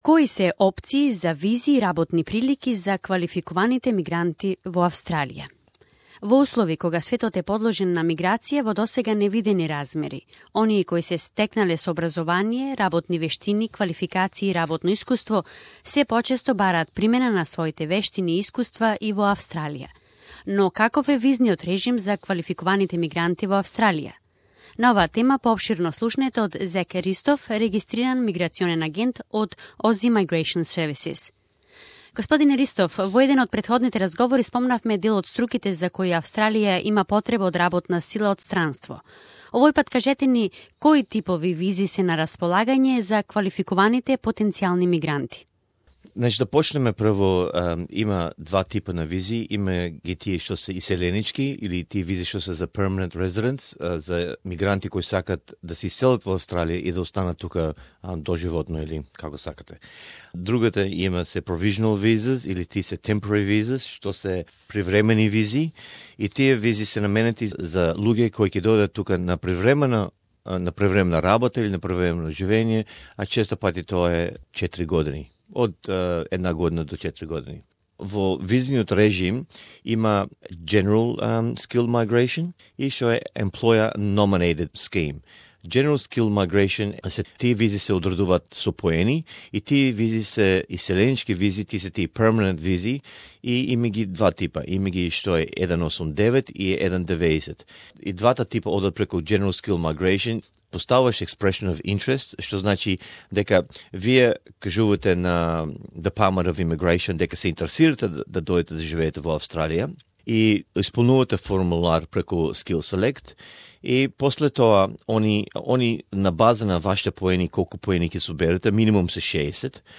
What are your visa options? Interview